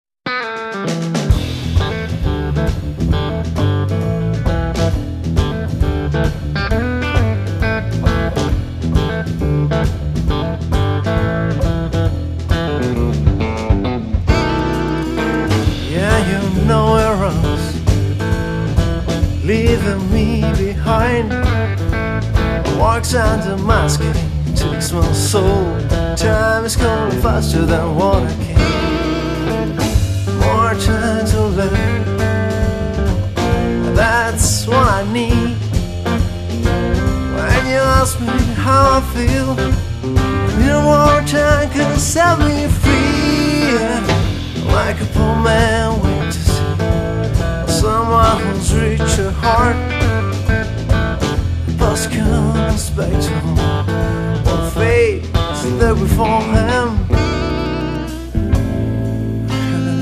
electric guitar & voice
double bass
drums & percussion
alto saxophone